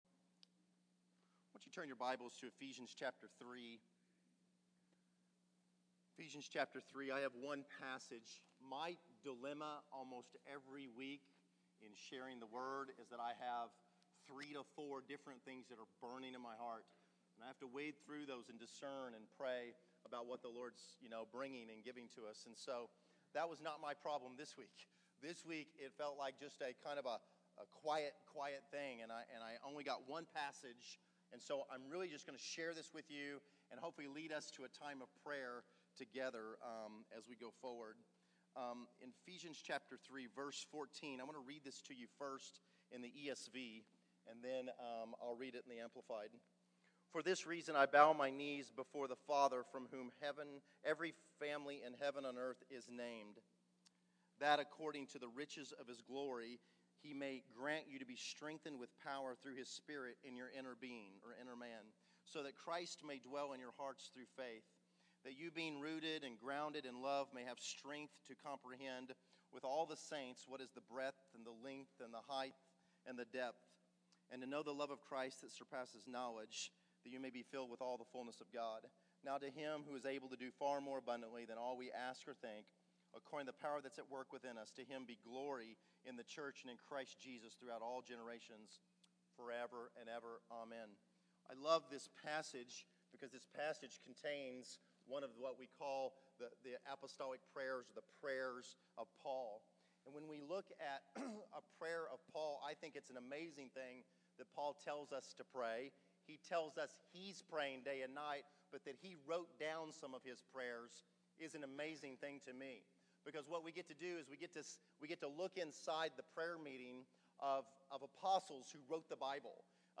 Category: Sermons | Location: El Dorado Back to the Resource Library